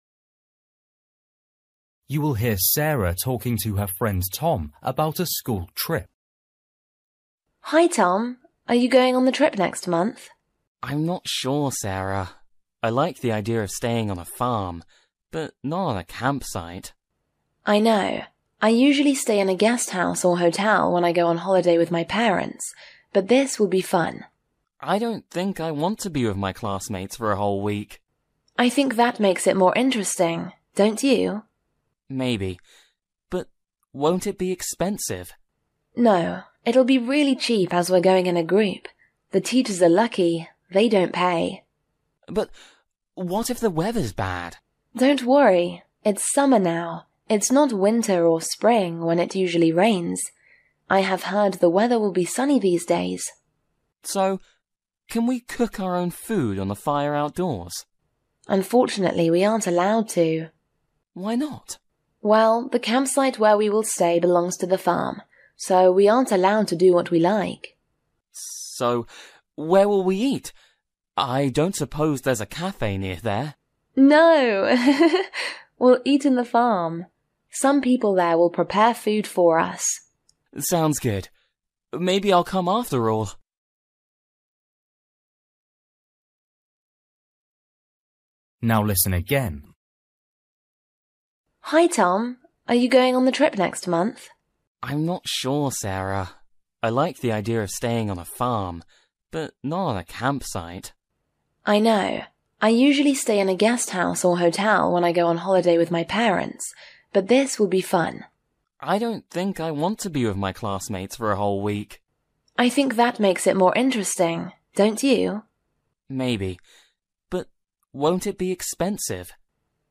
Bài tập trắc nghiệm luyện nghe tiếng Anh trình độ sơ trung cấp – Nghe một cuộc trò chuyện dài phần 15